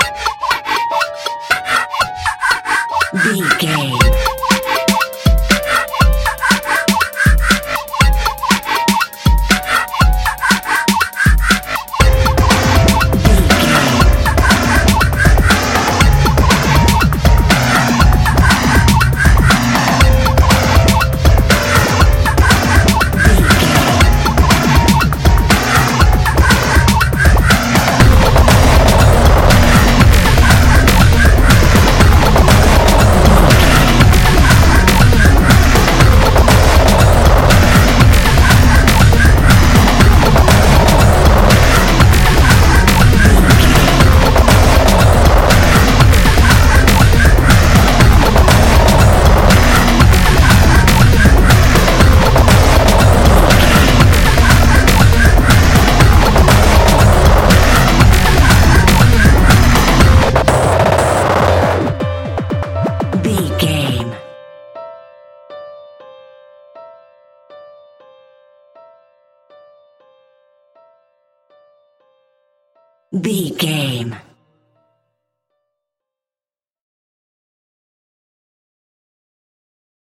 Fast paced
Atonal
intense
futuristic
energetic
driving
aggressive
dark
piano
synthesiser
drum machine
breakbeat
synth bass